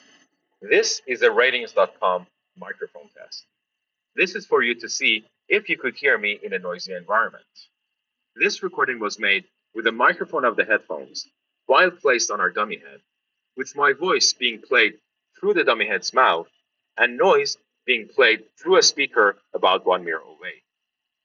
quiet and